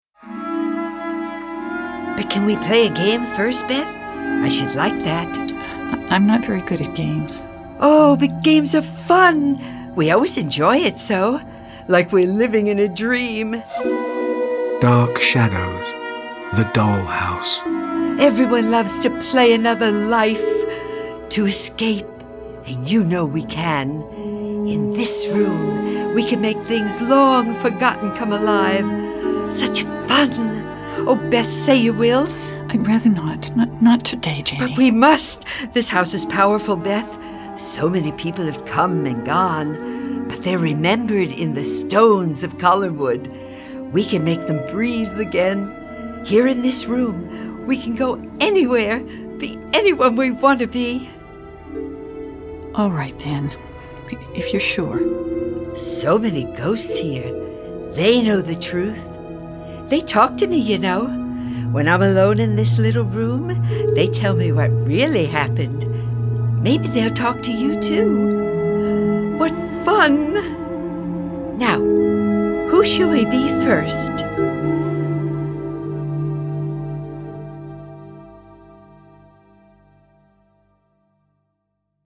14. Dark Shadows: The Doll House - Dark Shadows - Dramatised Readings